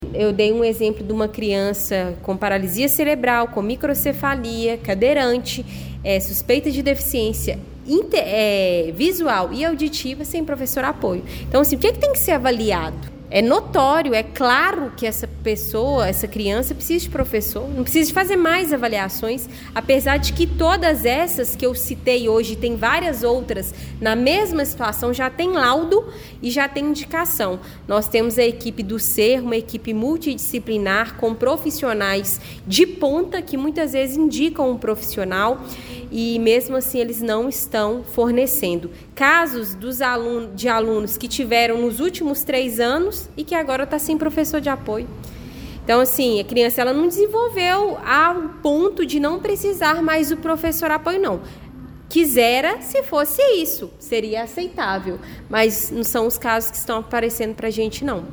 Cobrança por professores de apoio ganha destaque na tribuna
Após a retirada do projeto da pauta, Camila Gonçalves utilizou a tribuna para levantar outra preocupação considerada urgente: a ausência de professores de apoio para estudantes que necessitam desse acompanhamento na rede municipal de ensino de Pará de Minas.